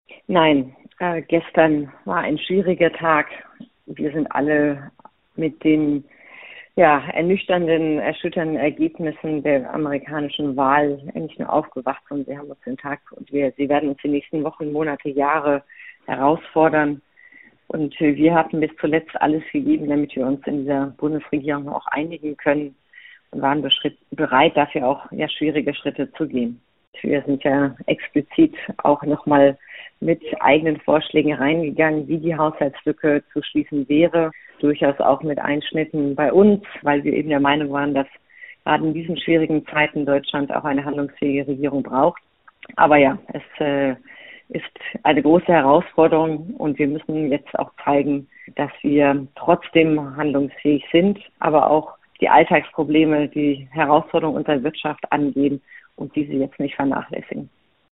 Die Heidelberger Bundestagsabgeordnete Franziska Brantner (Grüne) teilte dem SWR mit, die Grünen in der Bundesregierung hätten "zuletzt alles gegeben, damit wir uns in der Bundesregierung einigen können und waren bereit, dafür auch schwierige Schritte zu gehen". Man habe eigene Vorschläge eingebracht, wie man die Lücke im Bundeshaushalt schließen könne.